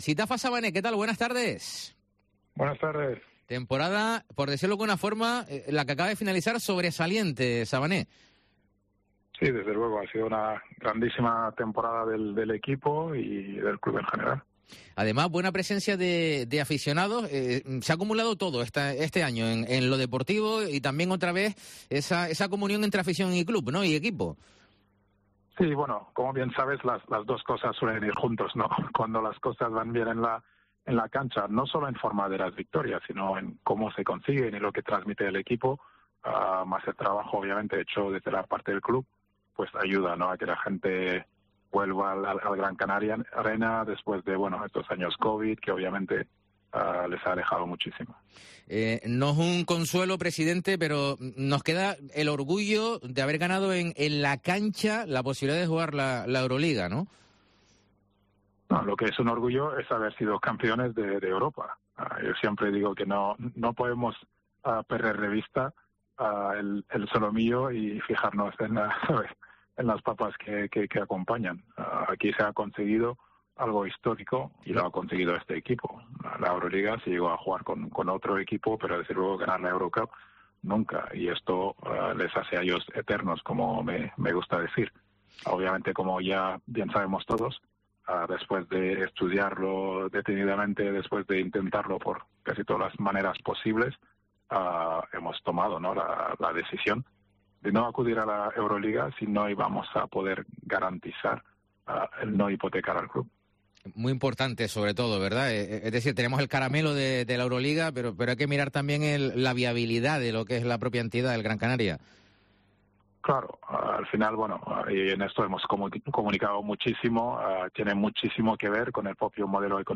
Entrevista a Sitapha Savané en Deportes COPE Gran Canaria